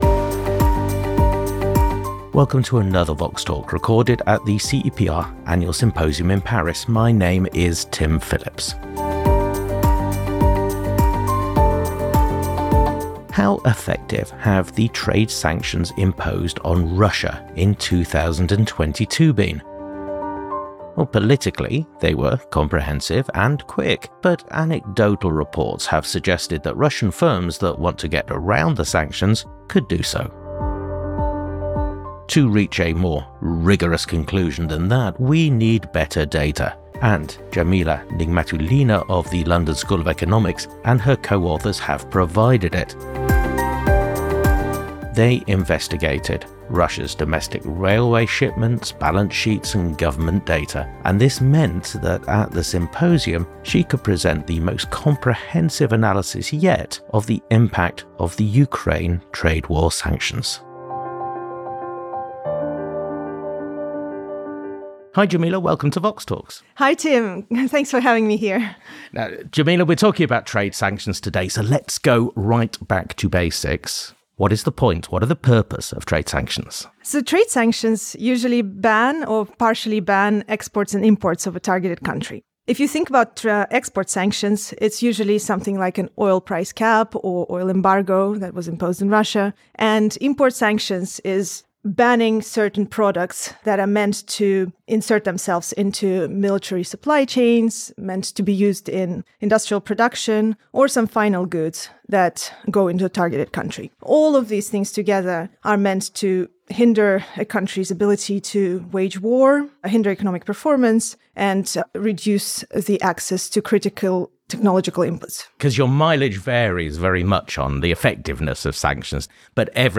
Recorded at the CEPR Paris Symposium. How effective have the trade sanctions imposed on Russia in 2022 been?